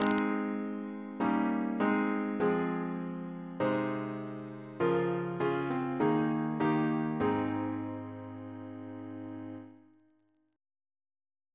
Single chant in A♭ Composer: George R. Woodward (1848-1934) Reference psalters: PP/SNCB: 231